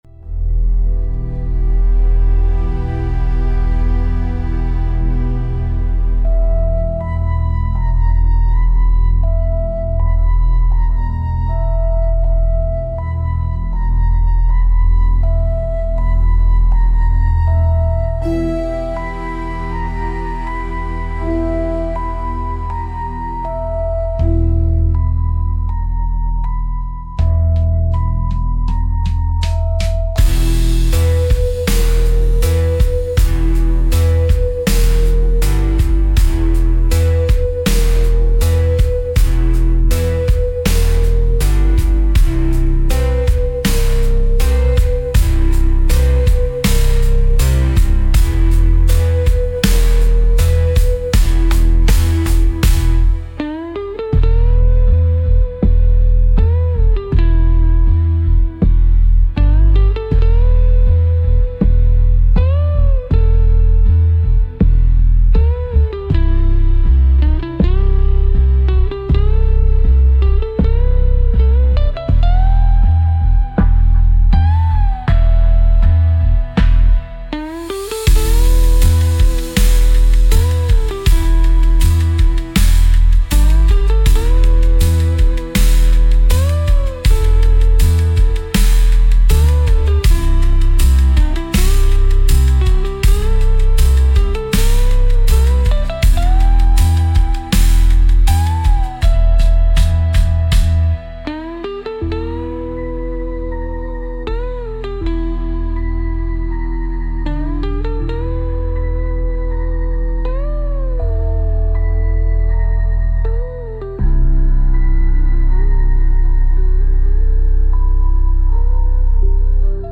Instrumental- Static Rituals - 4 mins